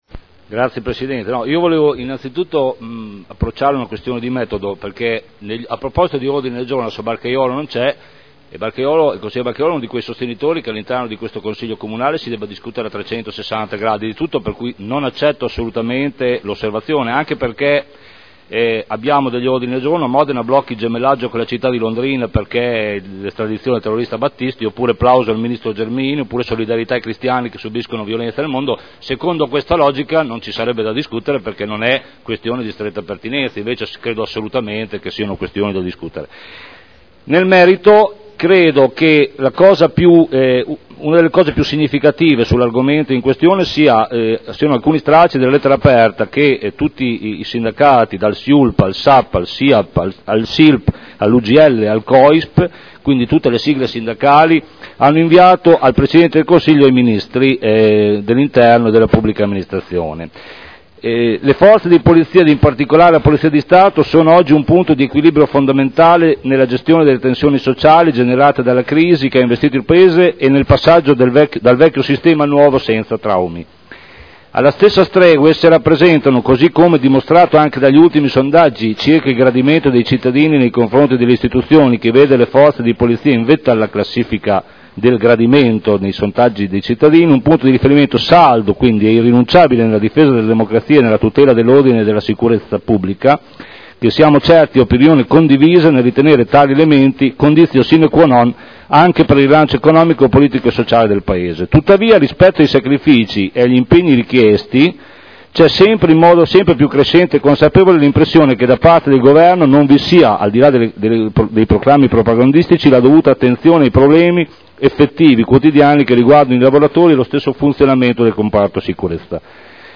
Giancarlo Campioli — Sito Audio Consiglio Comunale